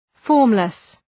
Leave a reply formless Dëgjoni shqiptimin https
{‘fɔ:rmlıs}